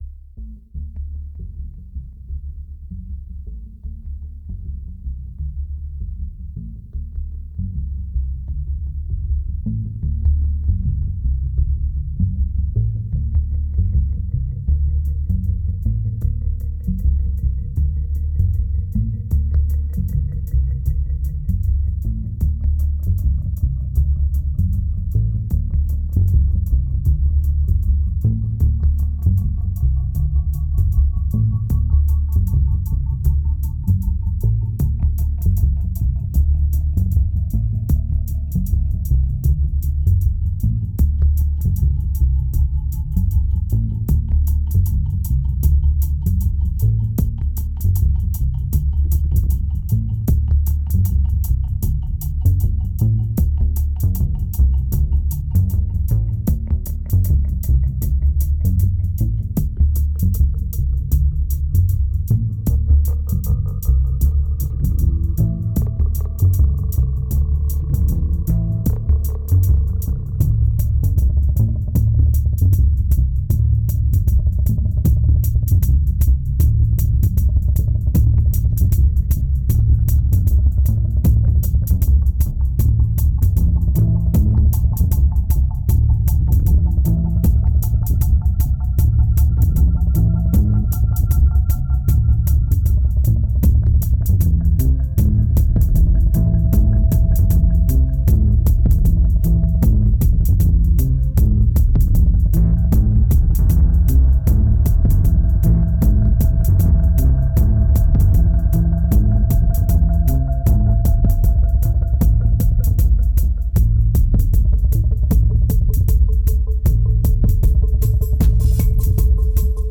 2064📈 - -22%🤔 - 155BPM🔊 - 2010-07-23📅 - -244🌟